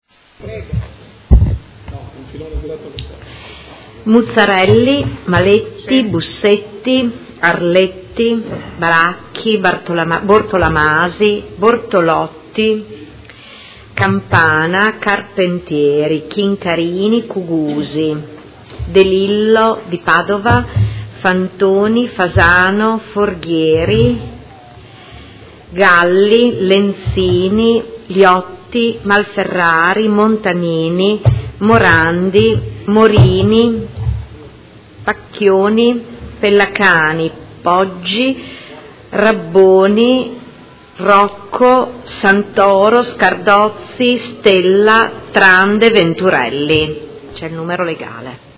Seduta del 20/04/2016. Appello